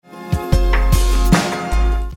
нужен такой семпл палочки/щелчка
чтобы не плодить темы. как сделать такие сэмплы, даже не знаю как их назвать - томы, перкуссия?? слышу, что фильтром обработано, но что это не пойму.